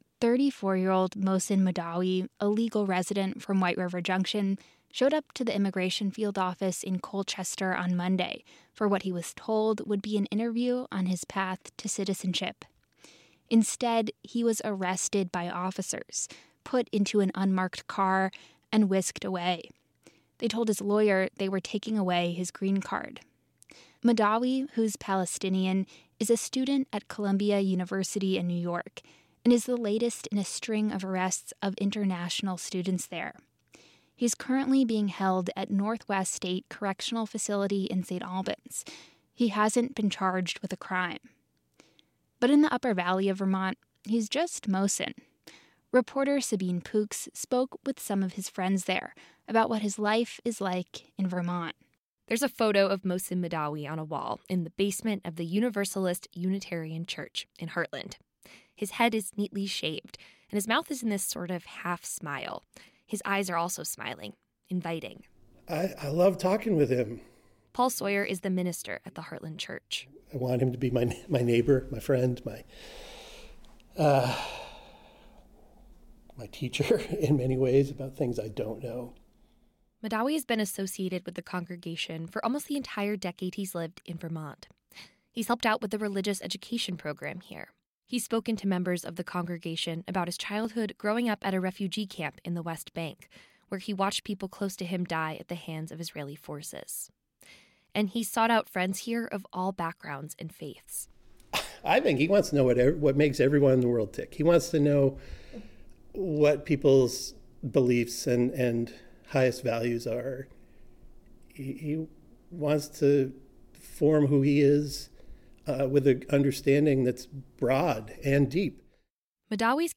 Local news, reporting and newscasts from Vermont Public.